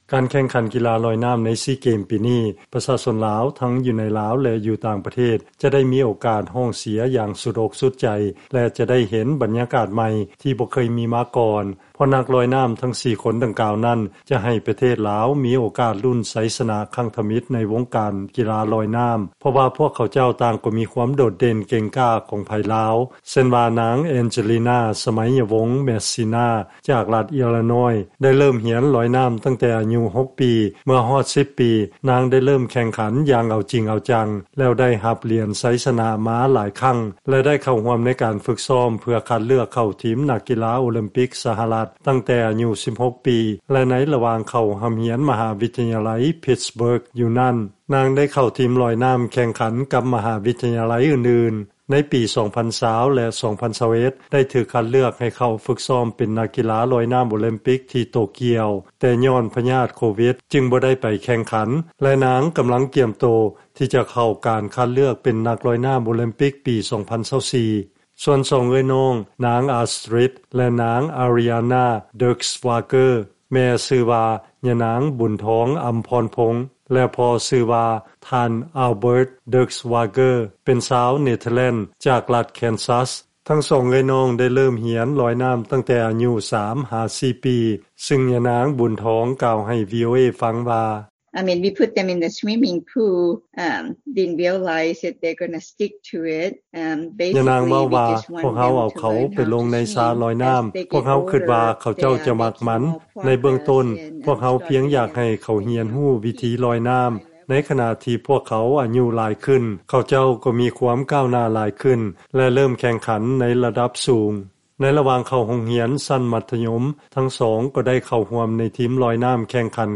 ຟັງລາຍງານ ນັກກິລາລອຍນ້ຳ ອາເມຣິກັນ ເຊື້ອສາຍລາວ ລະດັບມະຫາວິທະຍາໄລ ນຳເອົາຄວາມຫວັງ ມາໃຫ້ຊາວລາວ ໄດ້ລຸ້ນຫຼຽນ ຊີ ເກມ